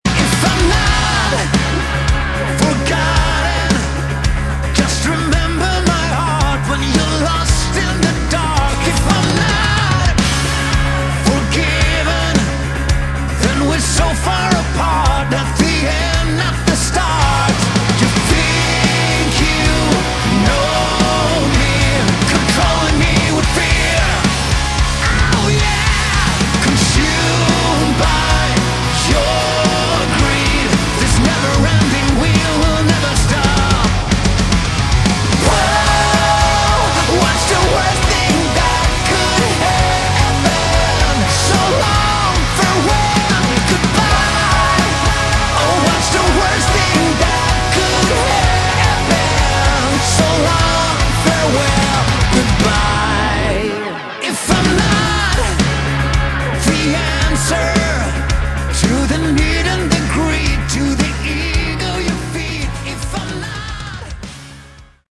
Category: Melodic Hard Rock
vocals
guitars
drums
bass